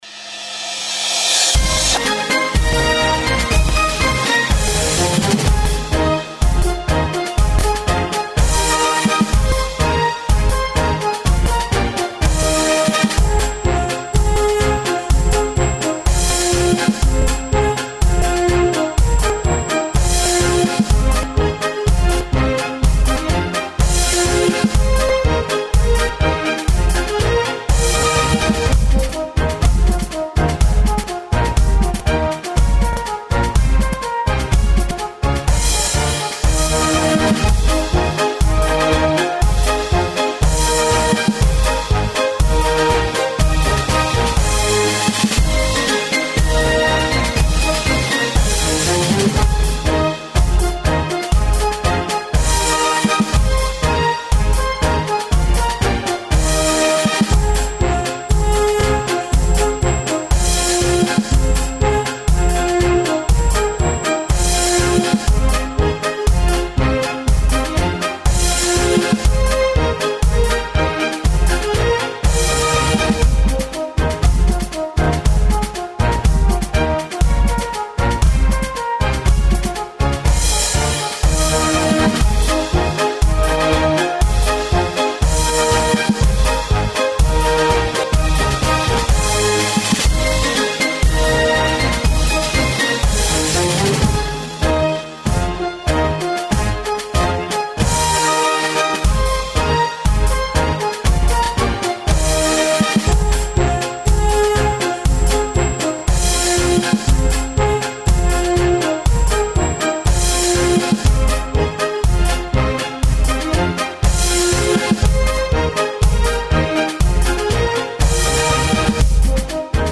合唱比赛伴奏